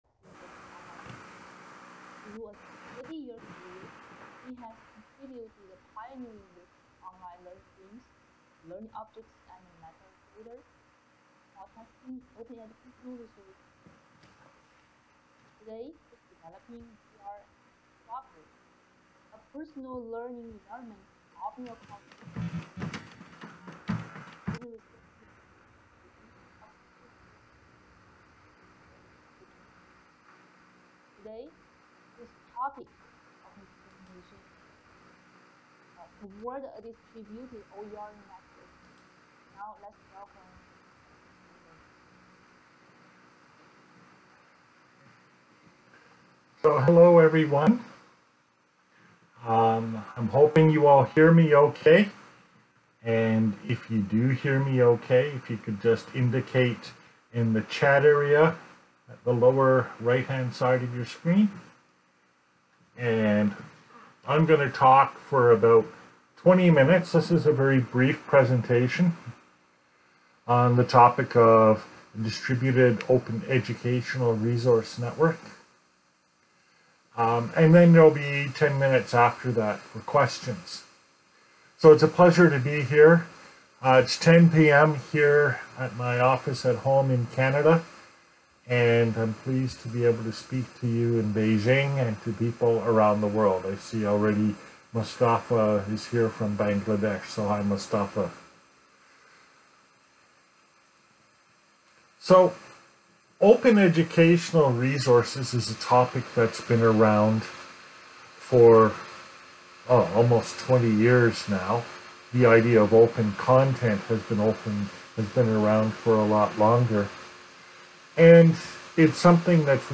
Webinar
via Adobe Connect